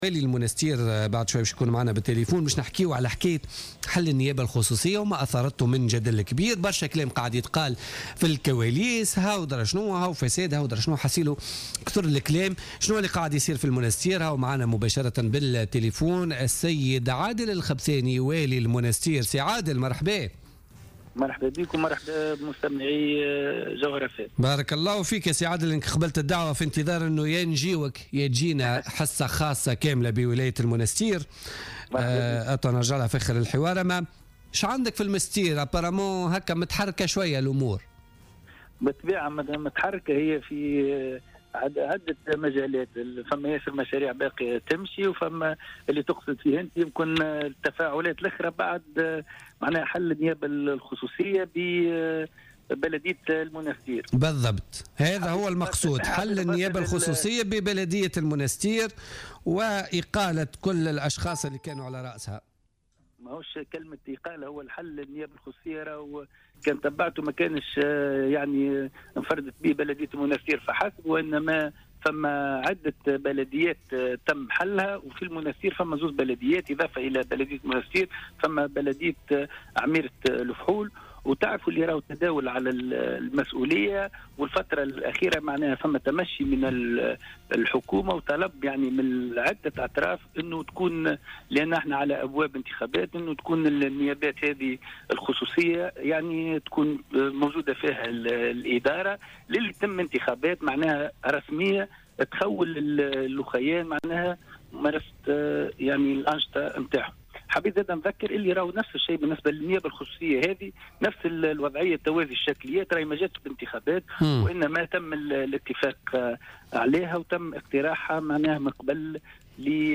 أكد عادل الخبثاني والي المنستير في مداخلة له في بوليتيكا اليوم الخميس 25 فيفري 2016 أن حل النيابة الخصوصية بالمنستير لم يشمل الولاية فقط بل شمل عدة نيابات خصوصية على كامل مناطق الجمهورية .